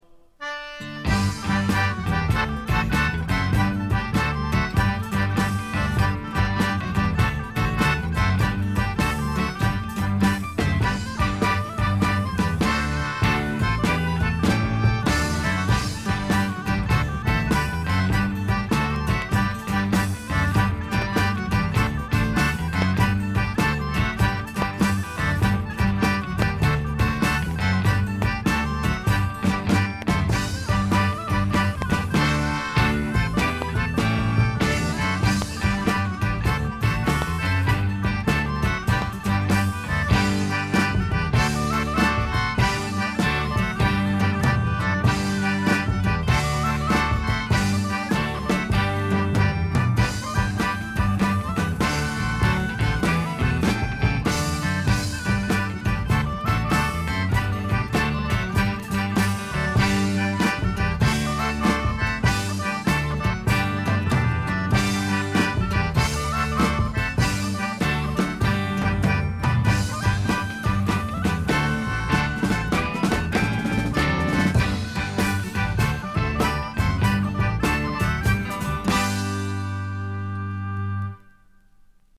A2d中盤周回気味ノイズ。
エレクトリック・トラッドの基本中の基本です。
試聴曲は現品からの取り込み音源です。